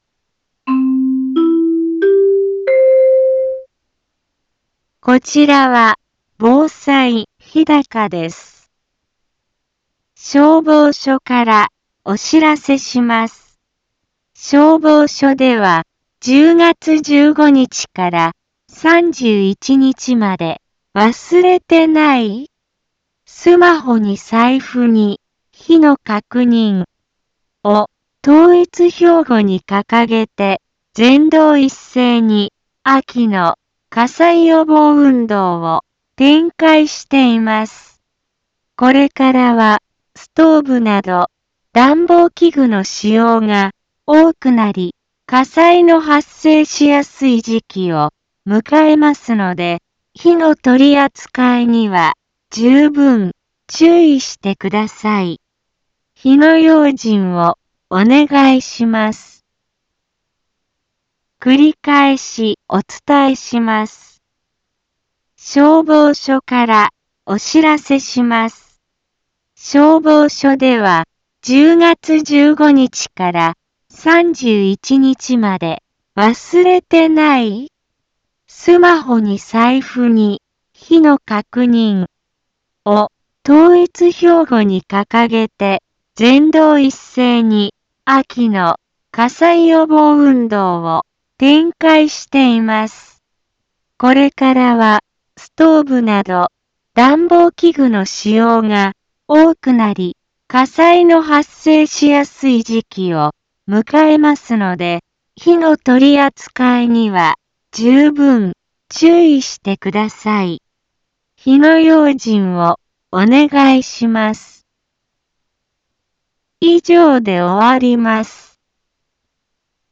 一般放送情報
Back Home 一般放送情報 音声放送 再生 一般放送情報 登録日時：2018-10-15 15:04:43 タイトル：秋の火災予防運動に伴う予防広報について インフォメーション：消防署から、お知らせします。